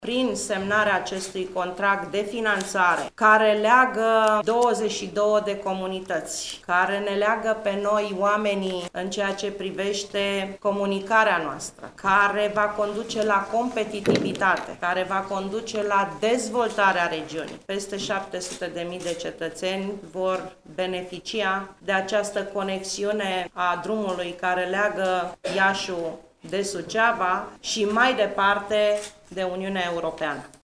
Cel mai important proiect de investiţii de infrastructura rutieră, cu fonduri europene,  a fost semnat, astăzi, la Iaşi, în prezenţa ministrului delegat pentru Fonduri Europene, Rovana Plumb.
Ministrul delegat pentru Fonduri Europene Rovana Plumb: